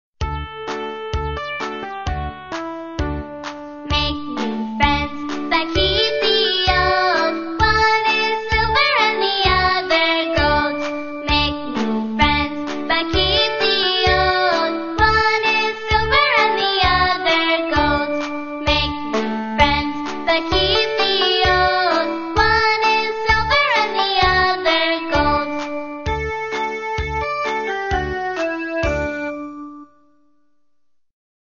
在线英语听力室英语儿歌274首 第131期:Make new friends的听力文件下载,收录了274首发音地道纯正，音乐节奏活泼动人的英文儿歌，从小培养对英语的爱好，为以后萌娃学习更多的英语知识，打下坚实的基础。